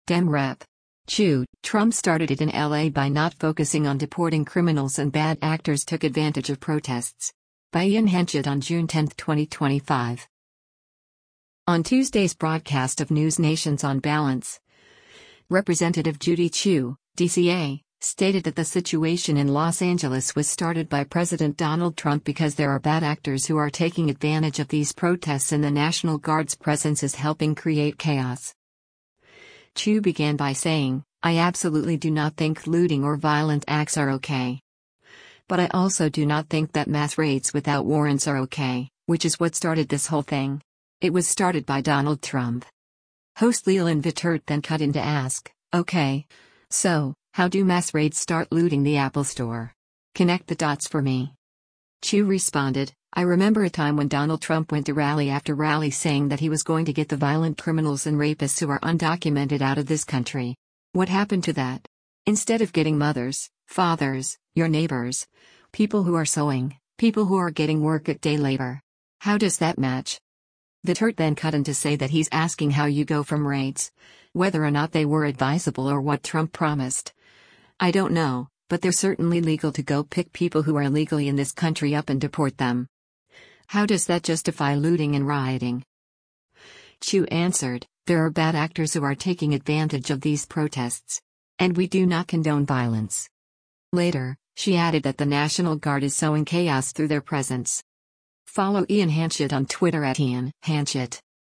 On Tuesday’s broadcast of NewsNation’s “On Balance,” Rep. Judy Chu (D-CA) stated that the situation in Los Angeles was started by President Donald Trump because “There are bad actors who are taking advantage of these protests” and the National Guard’s presence is helping create chaos.
Host Leland Vittert then cut in to ask, “Okay, so, how do mass raids start looting the Apple Store? Connect the dots for me.”